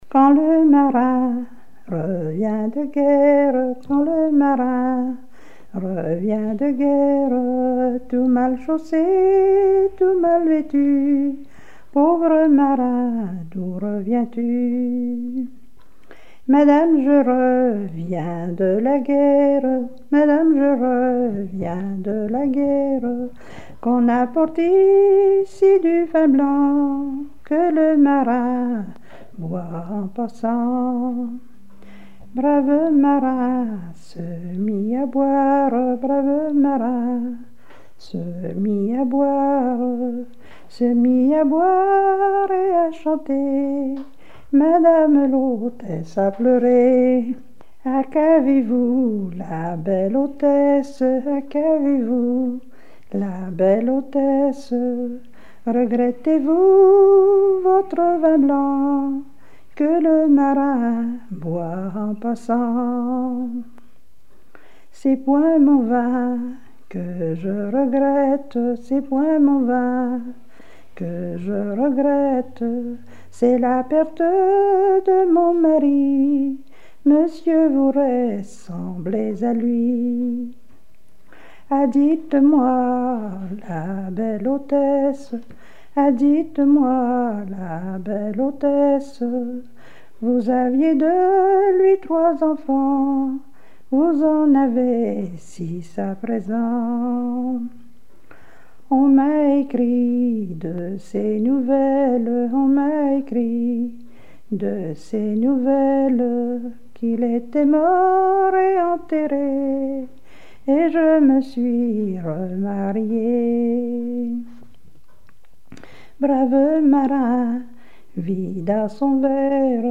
Mémoires et Patrimoines vivants - RaddO est une base de données d'archives iconographiques et sonores.
Genre strophique
Répertoire de chansons traditionnelles et populaires
Pièce musicale inédite